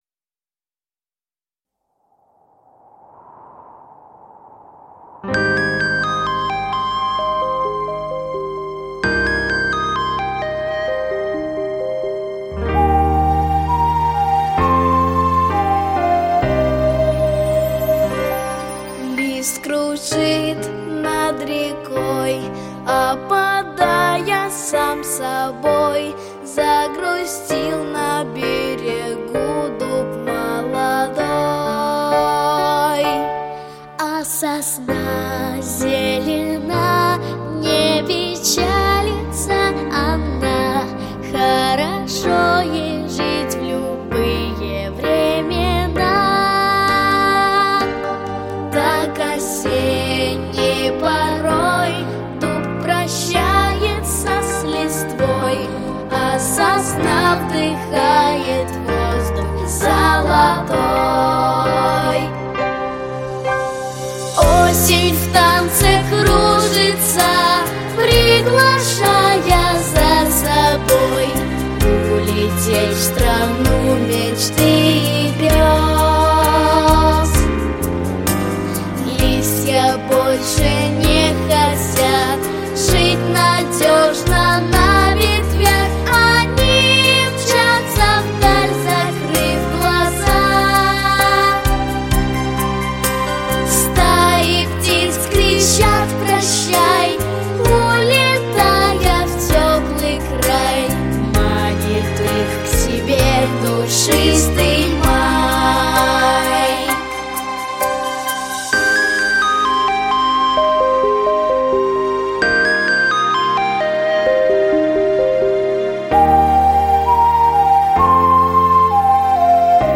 Главная / Песни для детей / Песни про осень